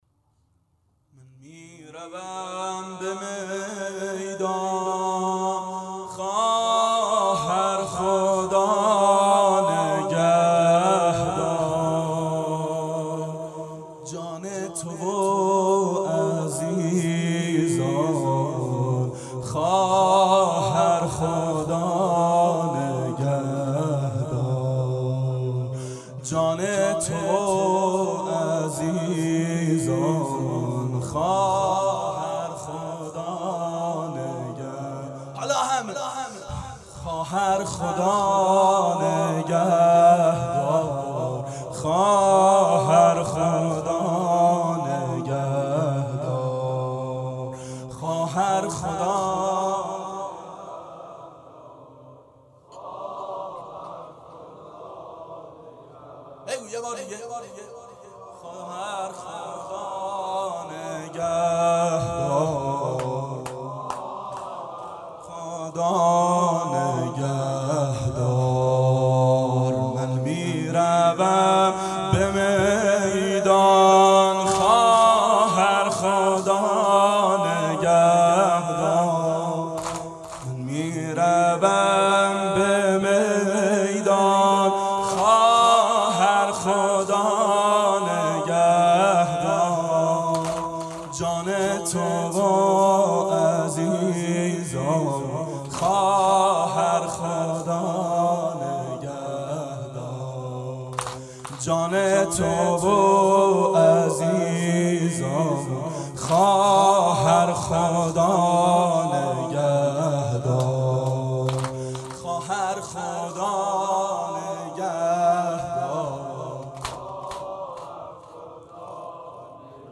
دهه اول صفر سال 1390 هیئت شیفتگان حضرت رقیه س شب اول